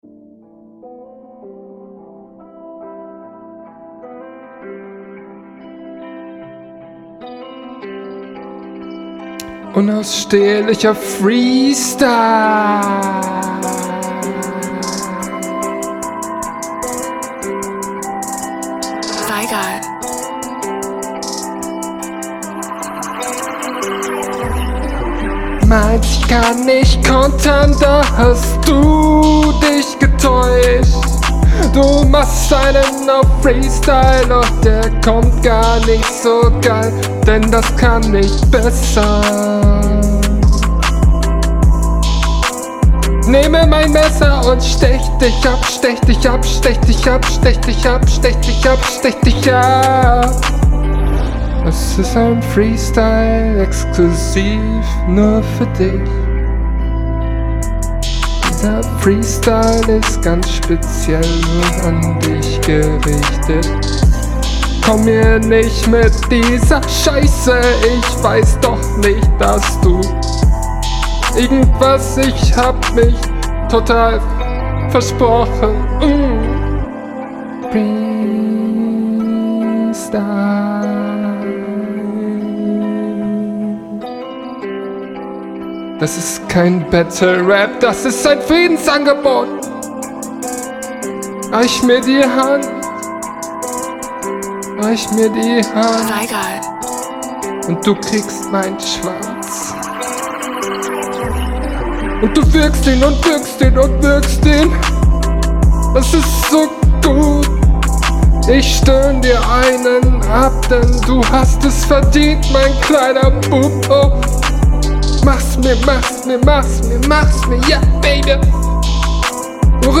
Leider absolut nicht im Takt mit dem Beat.